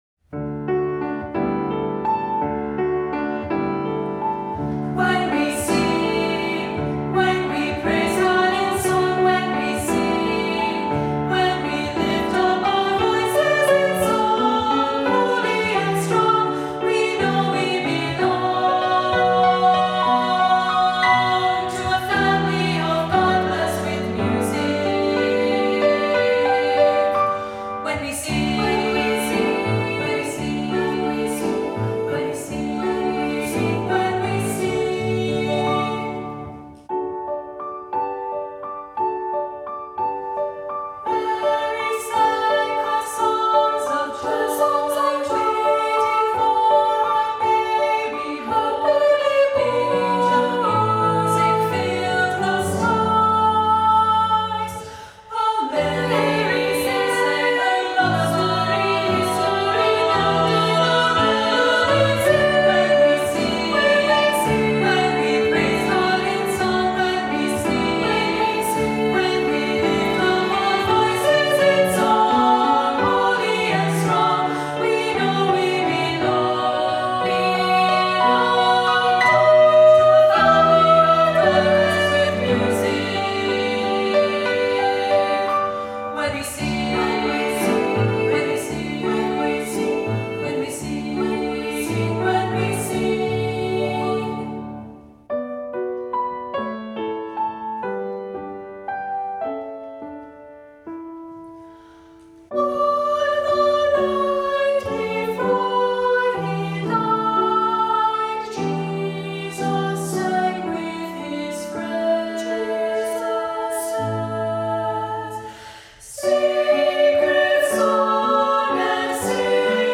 Voicing: 2-part Children’s Choir - SA